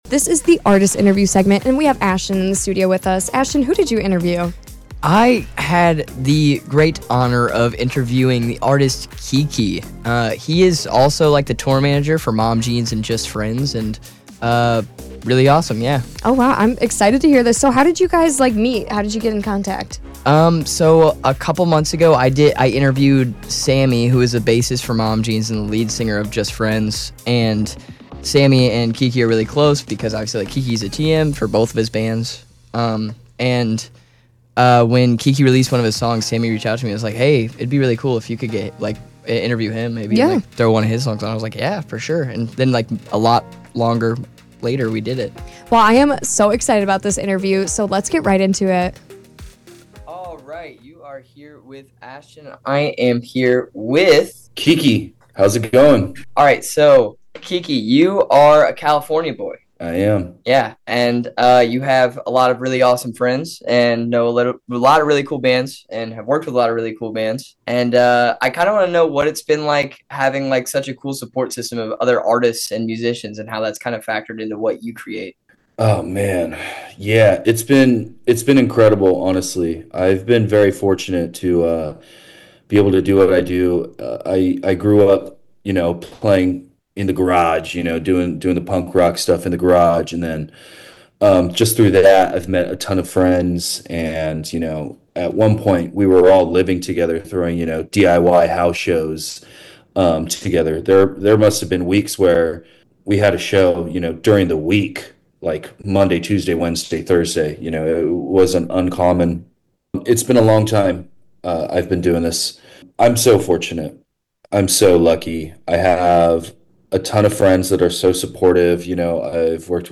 429interview-1.mp3